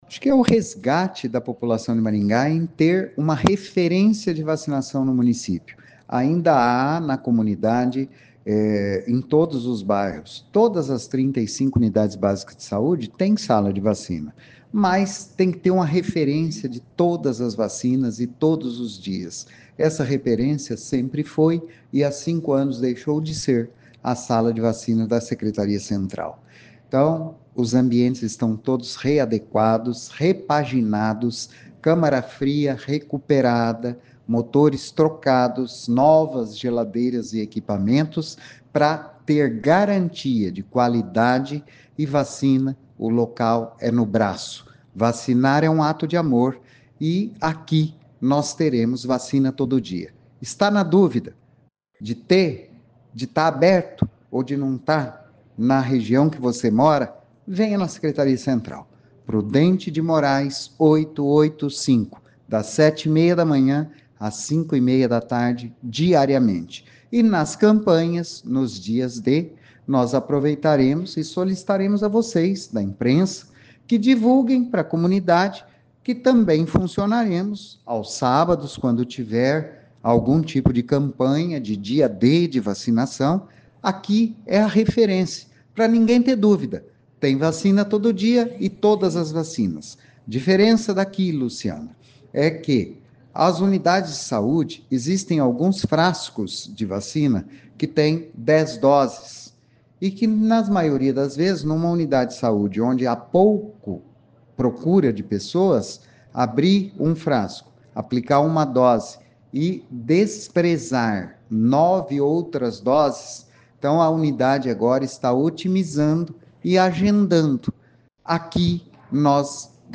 Ouça o que diz o secretário de Saúde Antônio Carlos Nardi.